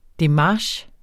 Udtale [ deˈmɑːɕ ]